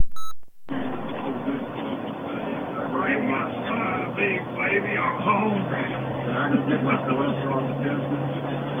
EVP
The voices are frequently whispers and can be intermixed with human conversation.
EVP from the Federal Camp at the Battle of Tunnel Hill.